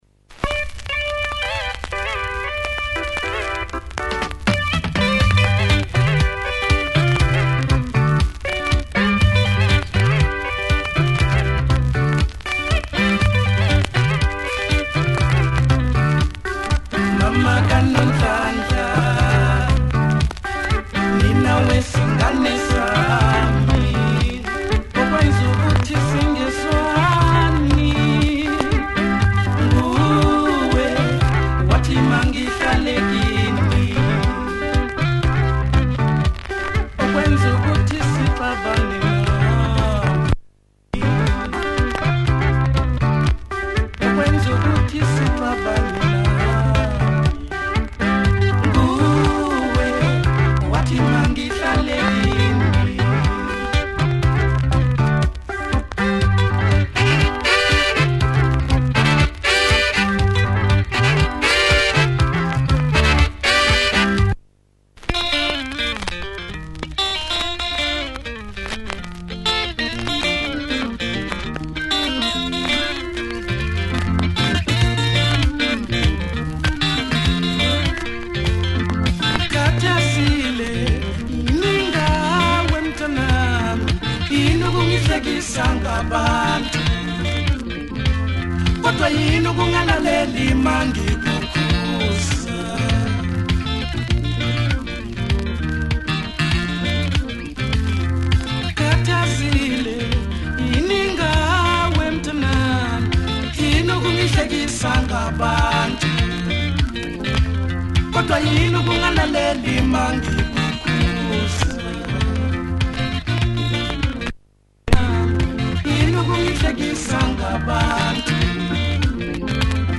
Nice Synth groove and horns on this one.